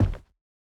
stepping sounds
BootsLinoleum_01.wav